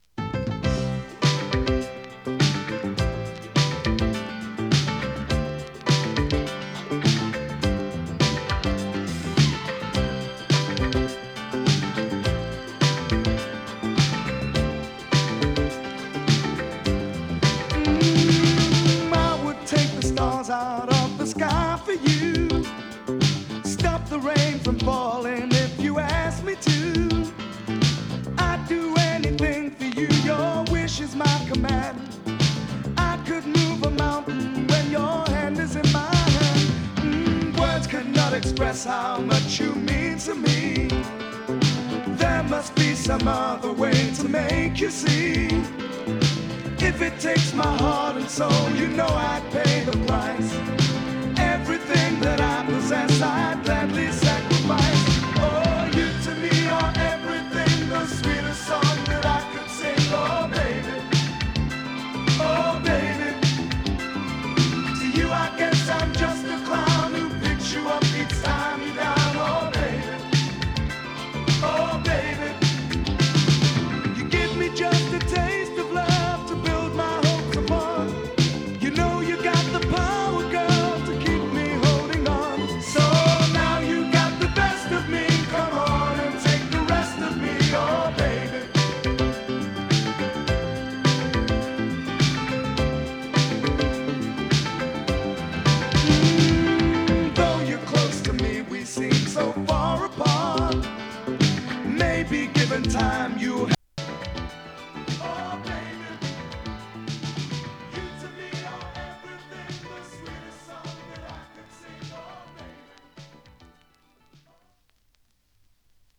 ソウル
[2track 7inch]＊音の薄い部分で軽いチリパチ・ノイズ。